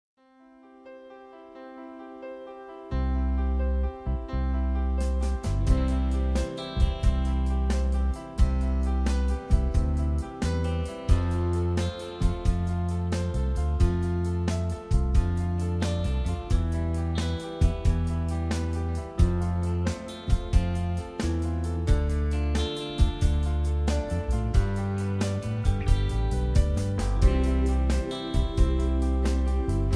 rock, easy listening